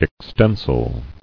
[ex·ten·sile]
Ex*ten"sile a. Suited for, or capable of, extension; extensible.